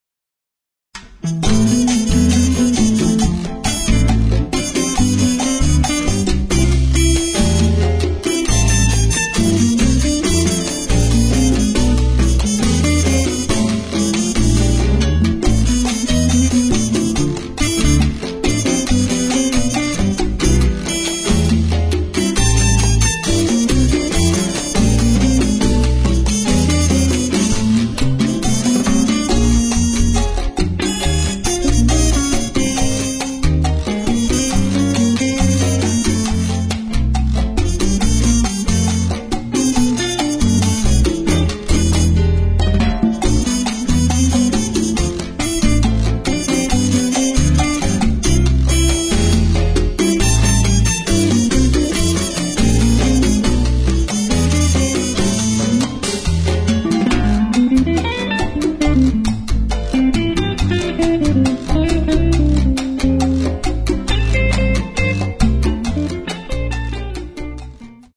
guitarra
Bajo, Contrabajo